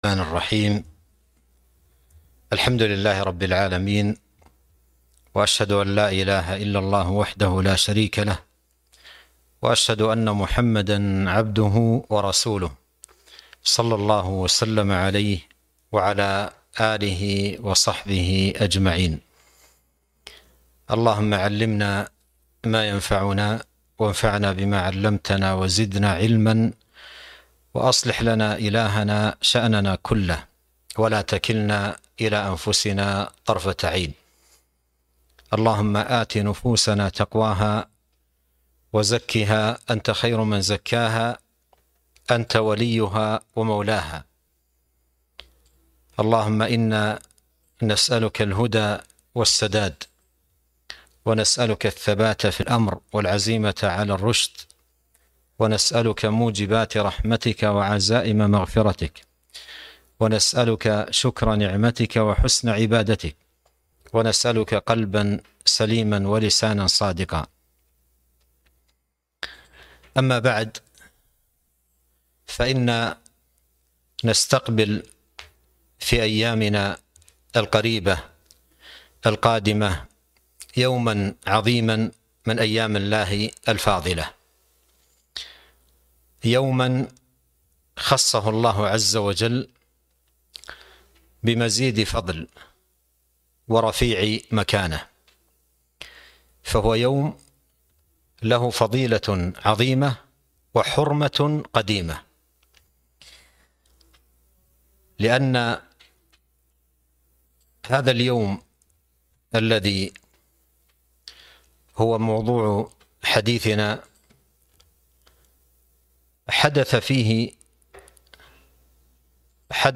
محاضرة - يوم عاشوراء 6-1-1443